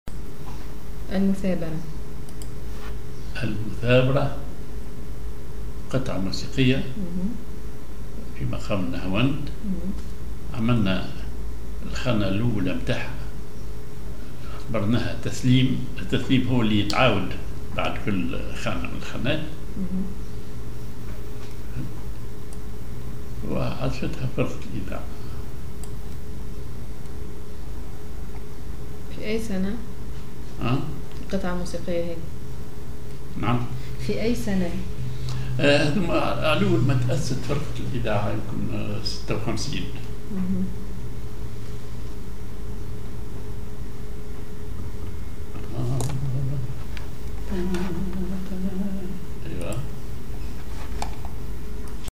Maqam ID نهاوند
معزوفة موسيقية